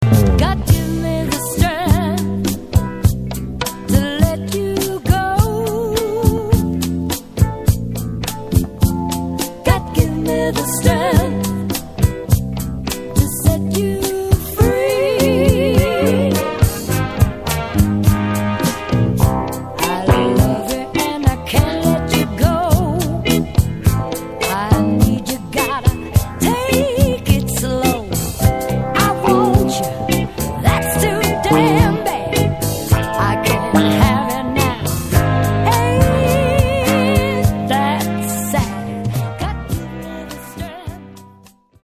FREE SOUL/RARE GROOVE
より都会的なグルーヴとメロウなテイストを増したサード・アルバム